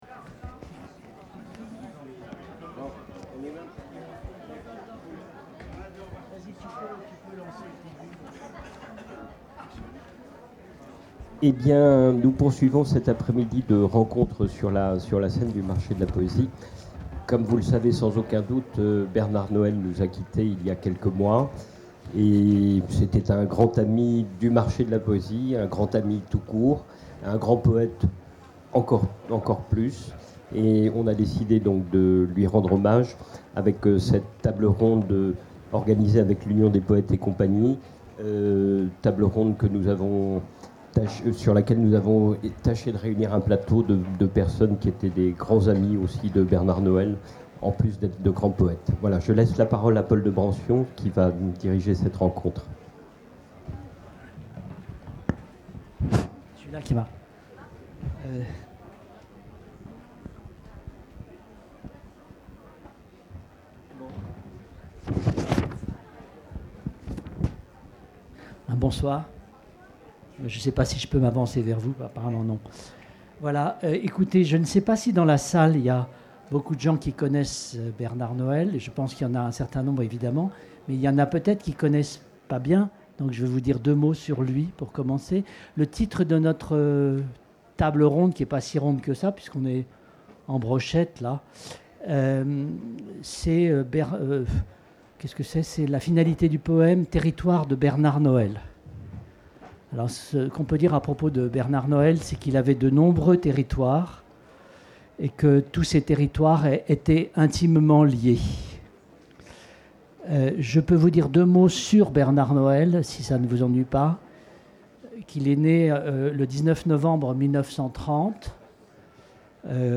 Scène Chapiteau du Marché Table ronde
Table-ronde-02-21.mp3